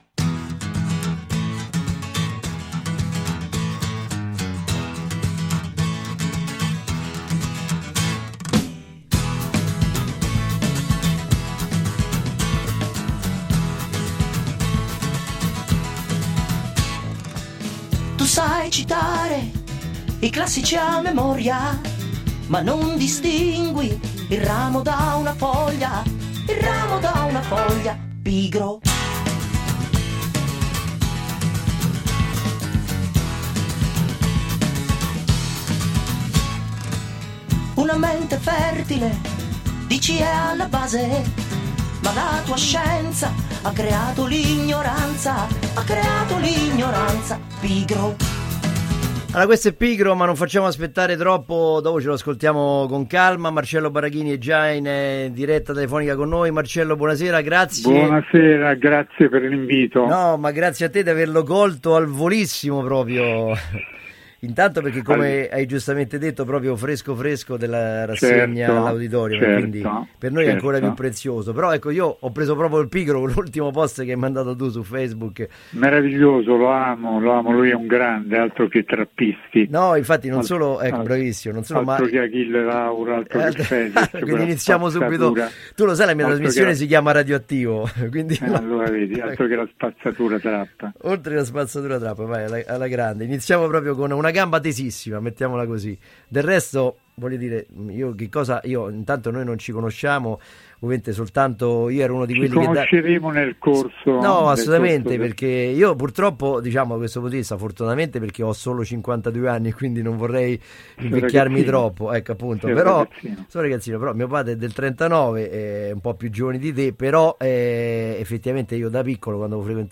Interviste Radio Attivo